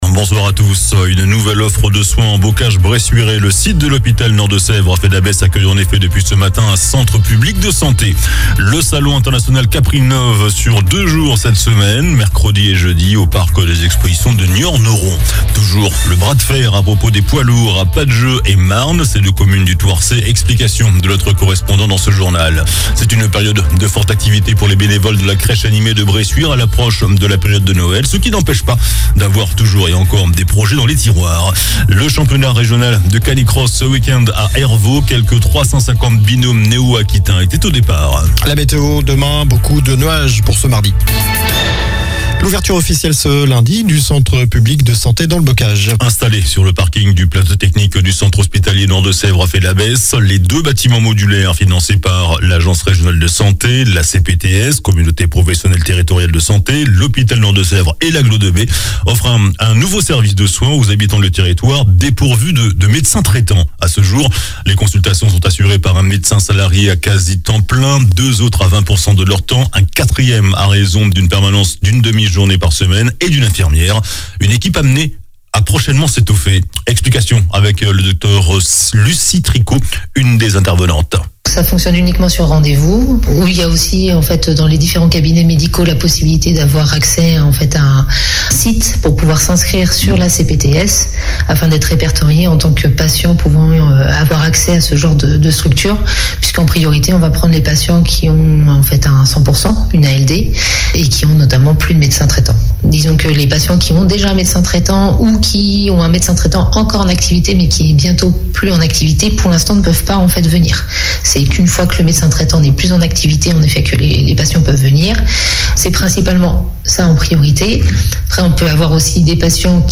Journal du lundi 20 novembre (soir)